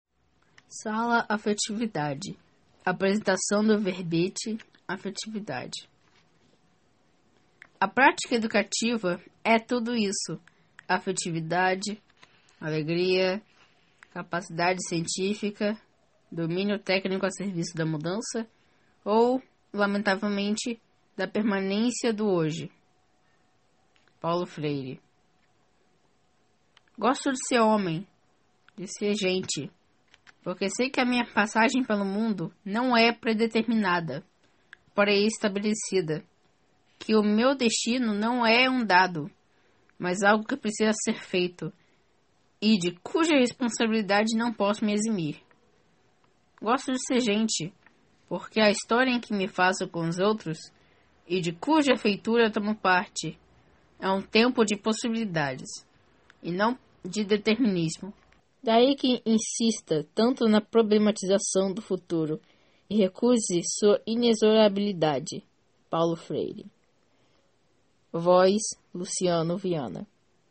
Verbete com voz humana